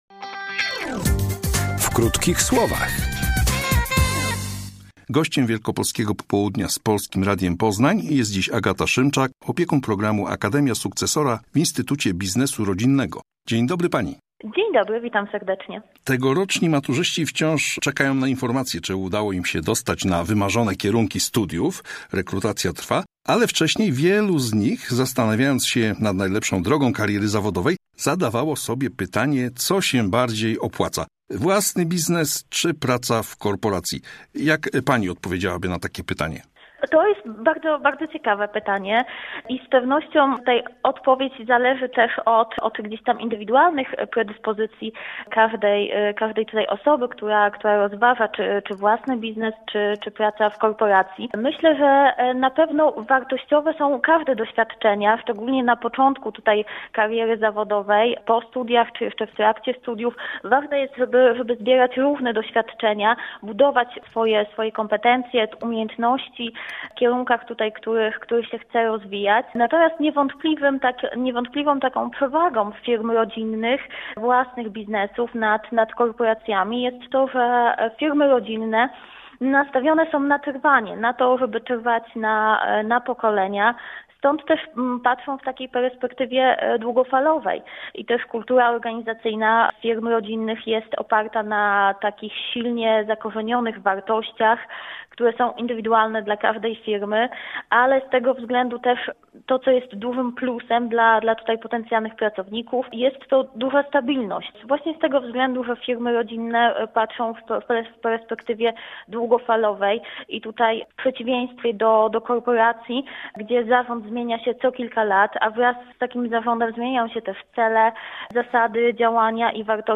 Firma rodzinna czy praca w korporacji? [ROZMOWA]